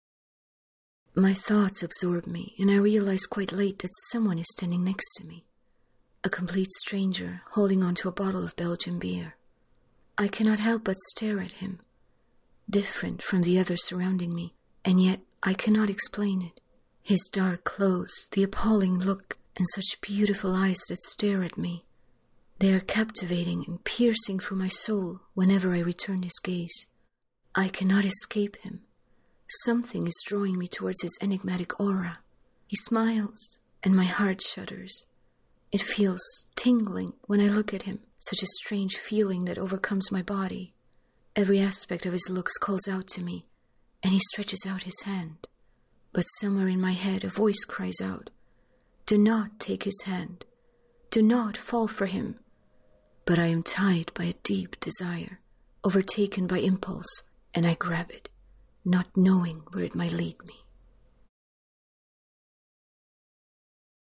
Du bist auf der Suche nach einer Sprecherin, die:
Englische Hörbücher